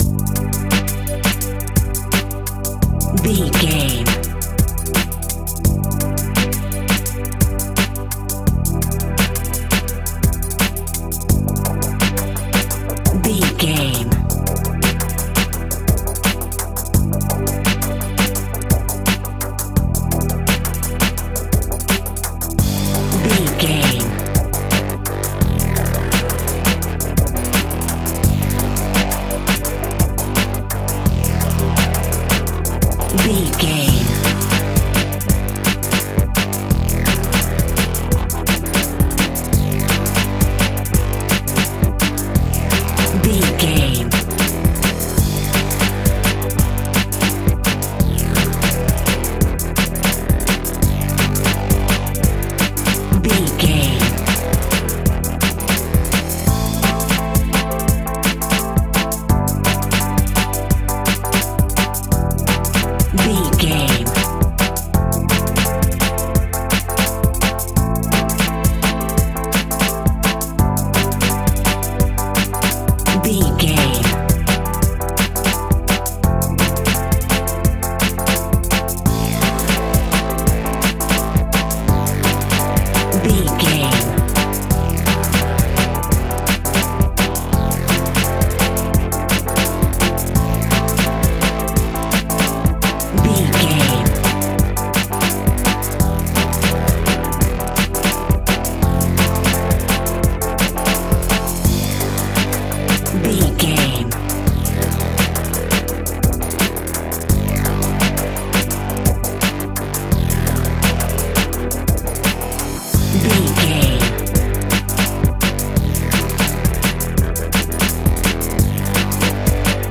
Aeolian/Minor
Fast
futuristic
hypnotic
industrial
dreamy
frantic
aggressive
powerful
synthesiser
drums
electronic
sub bass
synth leads
synth bass